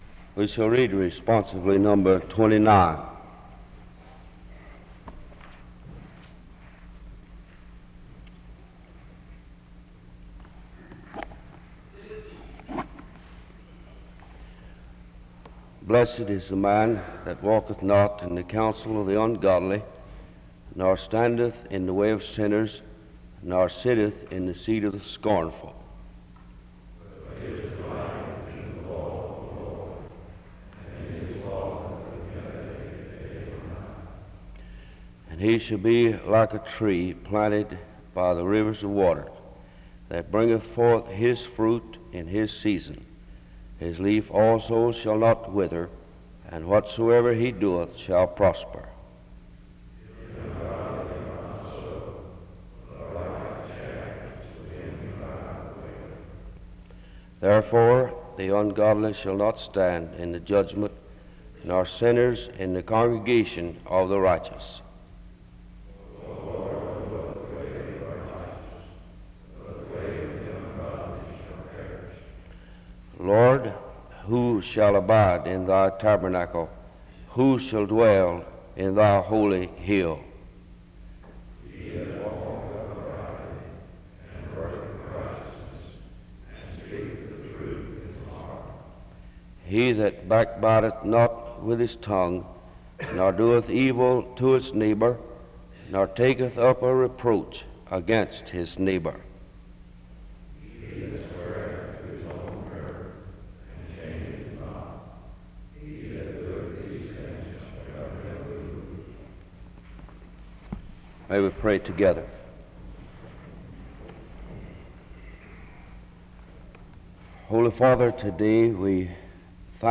SEBTS_Chapel_Student_Service_1968-02-16.wav